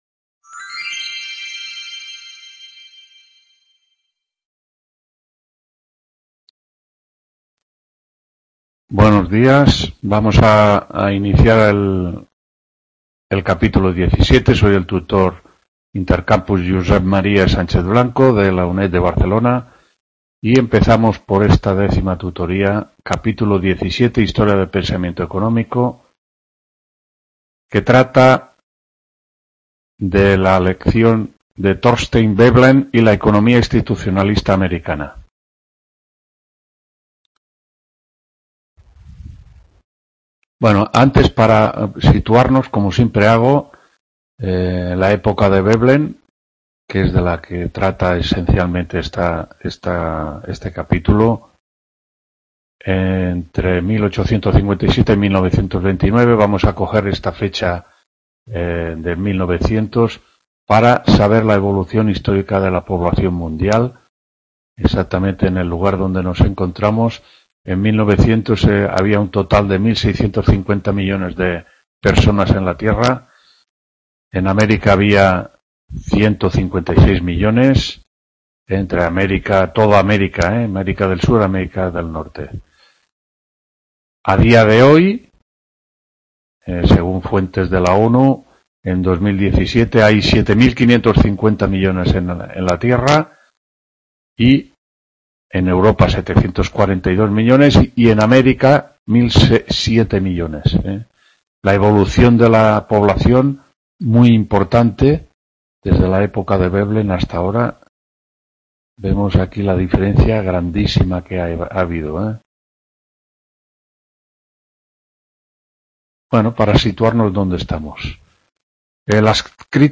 4ª TUTORÍA (Iª P) HISTORÍA DEL PENSAMIENTO ECONÓMICO… | Repositorio Digital